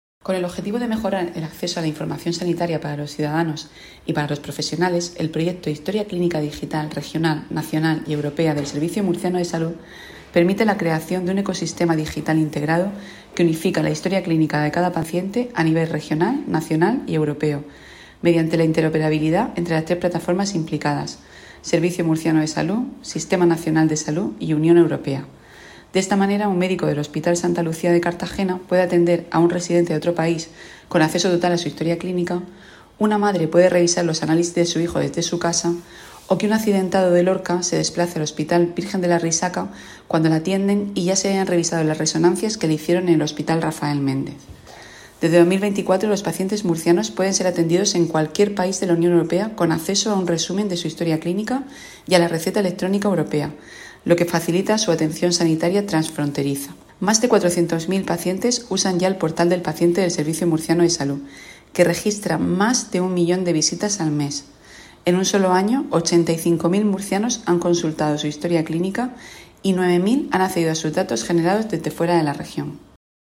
Sonido/ Declaraciones de la gerente del SMS, Isabel Ayala, sobre las ventajas que aporta la digitalización de la historia clínica de los pacientes.